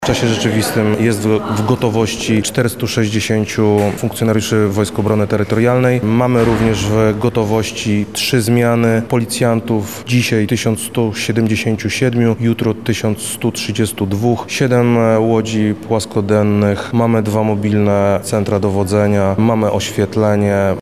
Krzysztof Komorski– mówi Wojewoda Lubelski, Krzysztof Komorski.